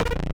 ui_extra_accept_v0.wav